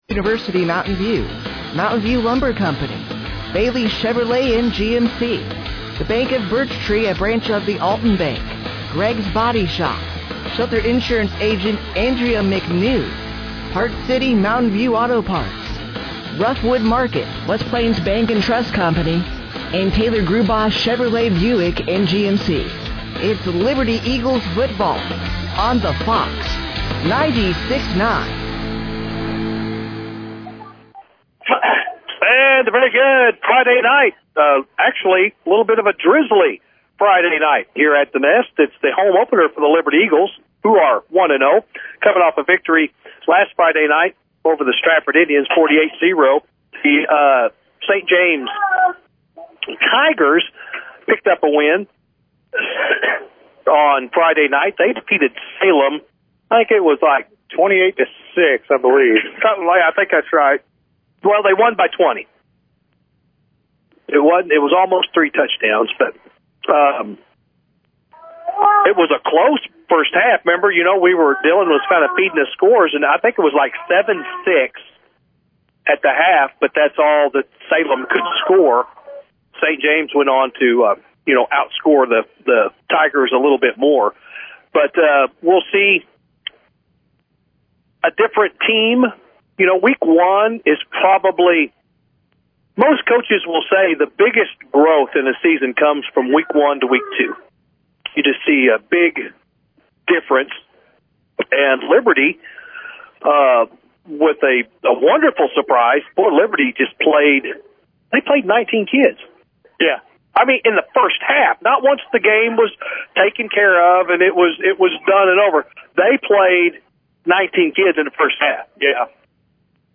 Liberty-Eagles-Football-vs.-St-James-Tigers-9-5-25.mp3